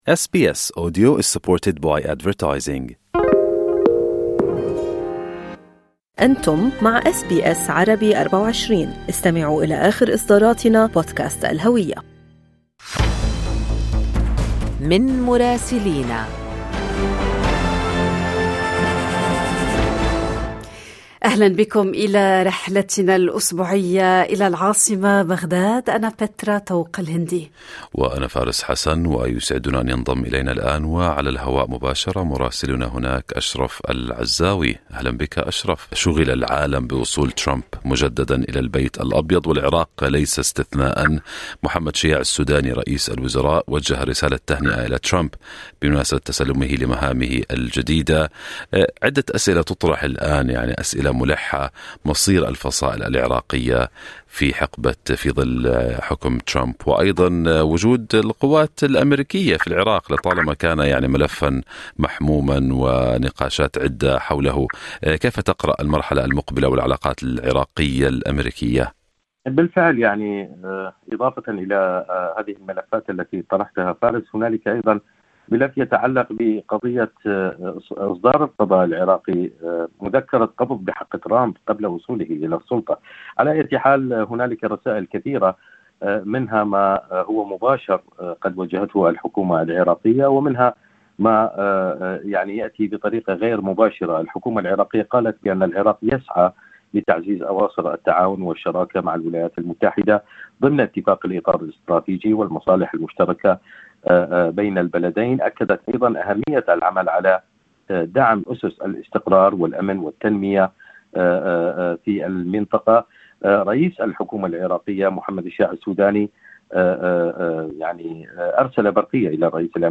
أهم أخبار الدول العربية مع مراسلينا من لبنان ومصر والأراضي الفلسطينية والعراق والولايات المتحدة.